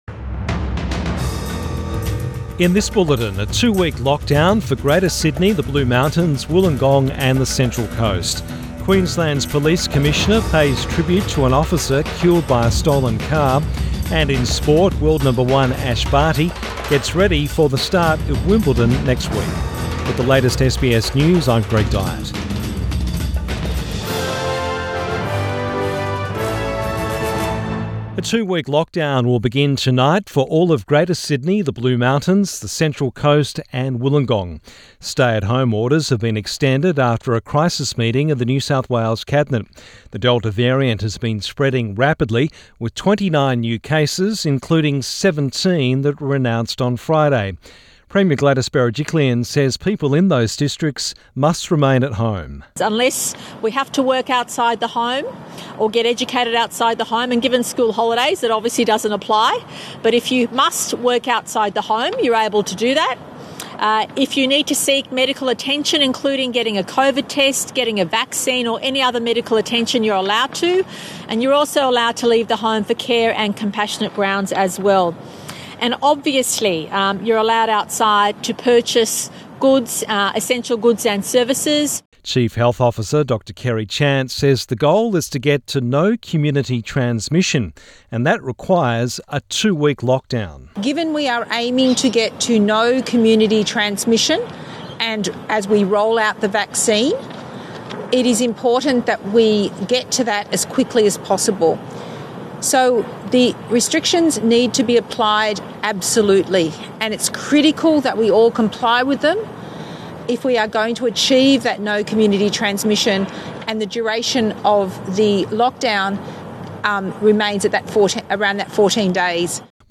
PM bulletin 26 June 2021